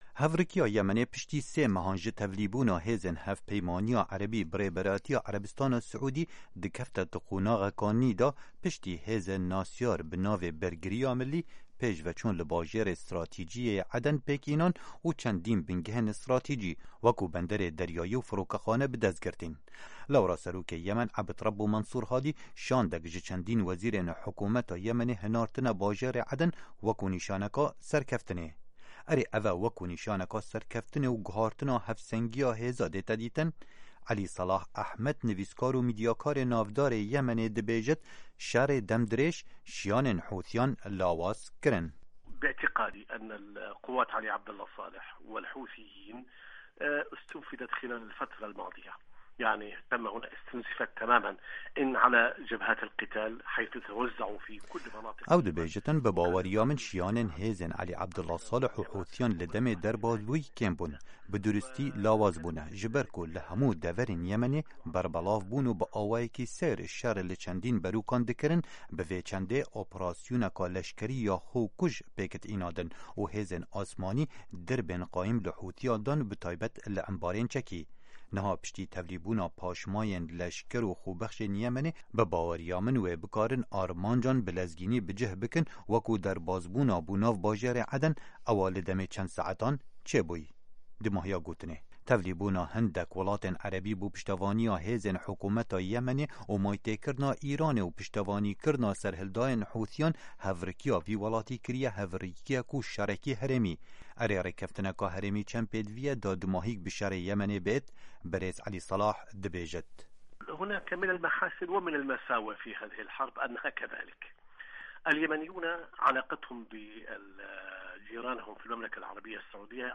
hevpeyvînekê